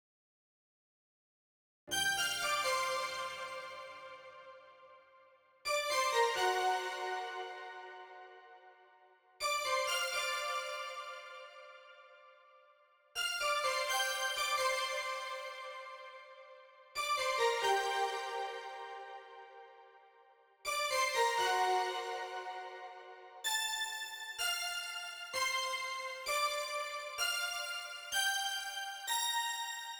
07 staccato pad B.wav